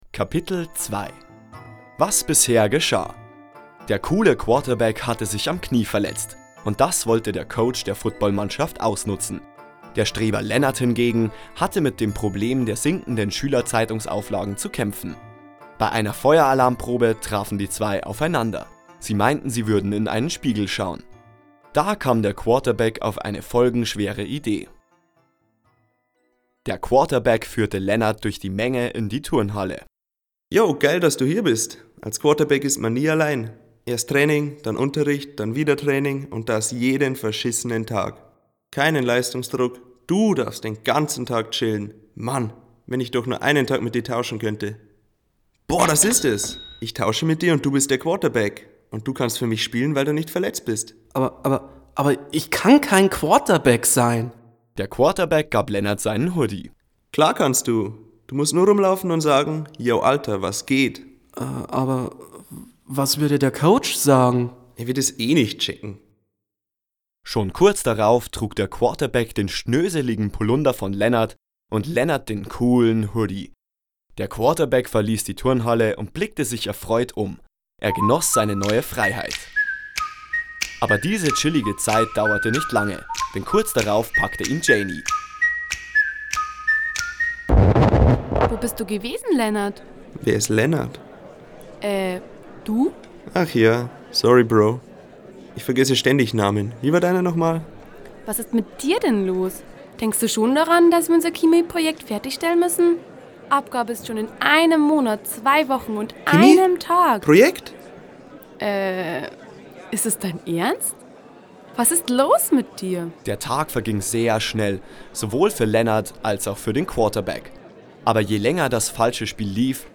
Hoerspiel_NichtNochEinTeeniHoerspiel_Teil2.mp3